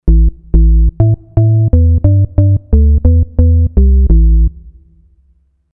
Чистый звук девайса, никаких эффектов.
Вложения Bass Organ DX 27.mp3 Bass Organ DX 27.mp3 224,5 KB · Просмотры: 801